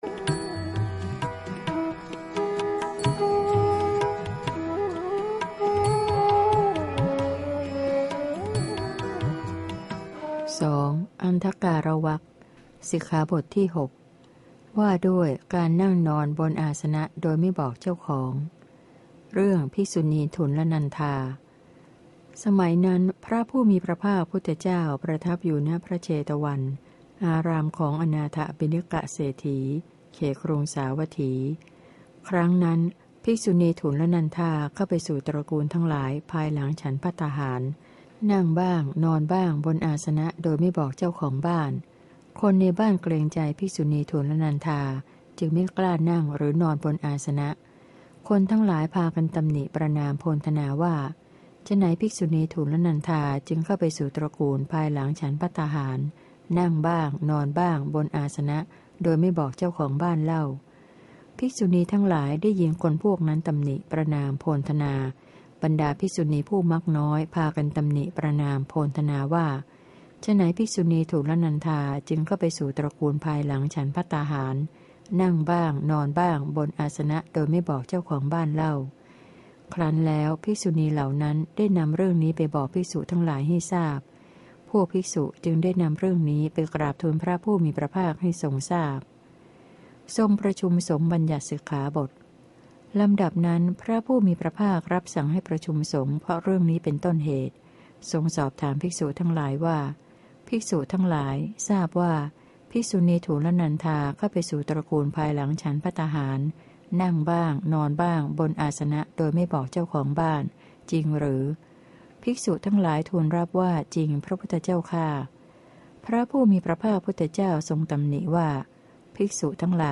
พระไตรปิฎก ภาคเสียงอ่าน ฉบับมหาจุฬาลงกรณราชวิทยาลัย - พระวินัยปิฎก เล่มที่ ๓